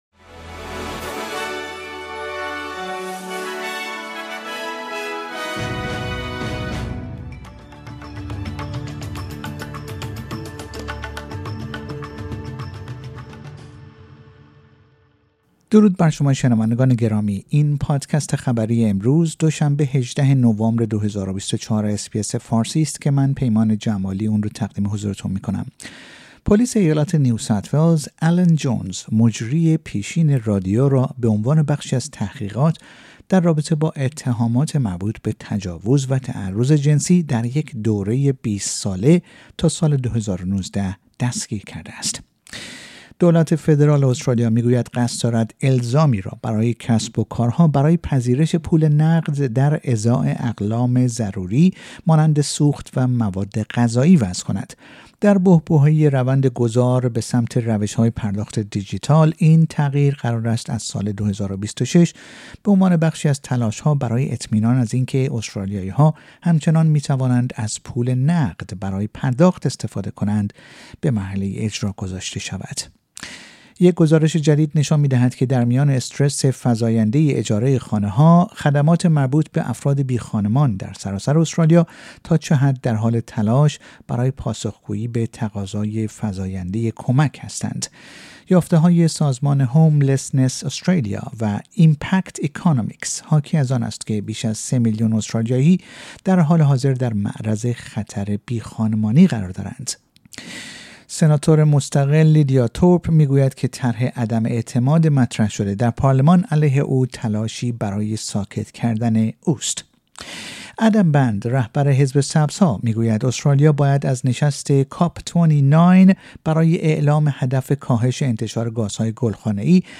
در این پادکست خبری مهمترین اخبار استرالیا در روز دوشنبه ۱۸ نوامبر ۲۰۲۴ ارائه شده است.